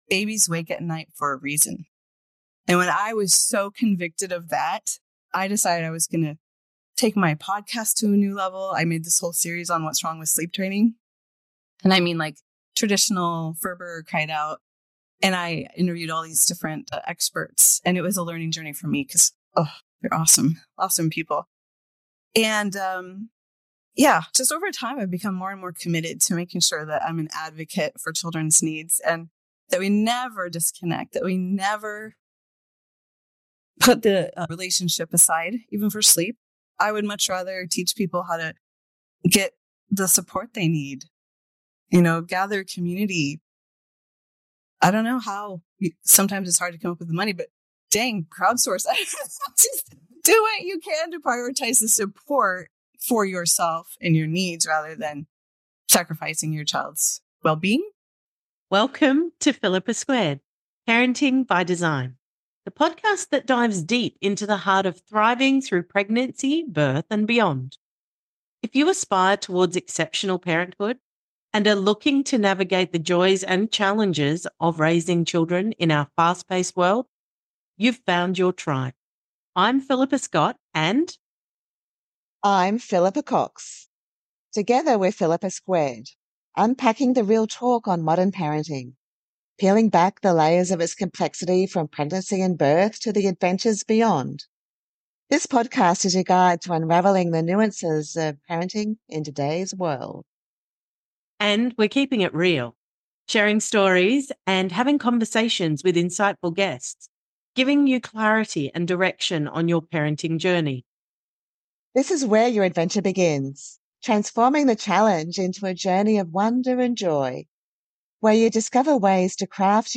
Join us for an enlightening conversation that will empower parents to embrace their instincts and create peaceful nights for their families.